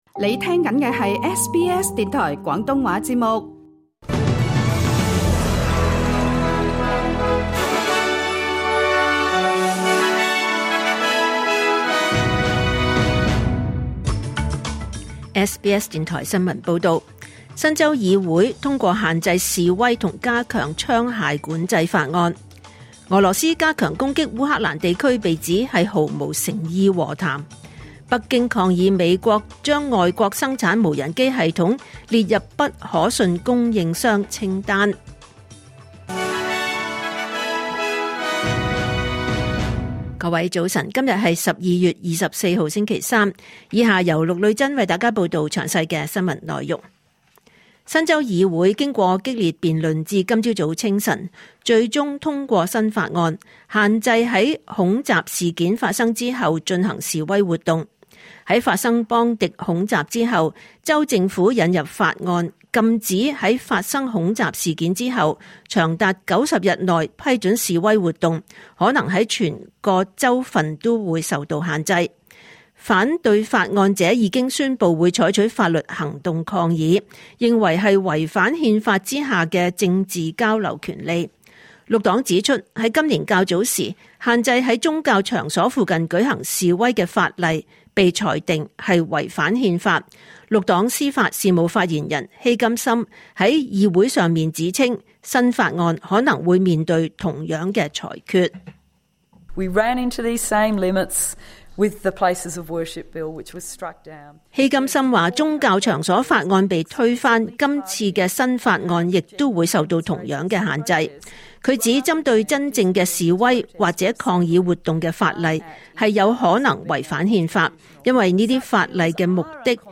2025年12月24日SBS廣東話節目九點半新聞報道。